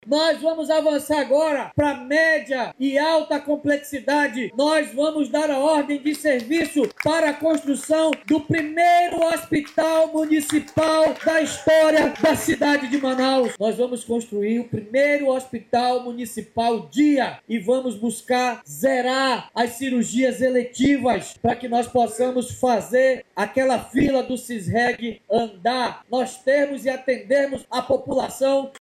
David Almeida ressaltou que, mesmo com menor participação na arrecadação tributária, é o município quem assume a maior parte da prestação direta de serviços à população. Ao final do discurso, o prefeito anunciou um novo passo na política de saúde do município: a construção do primeiro hospital municipal da história de Manaus.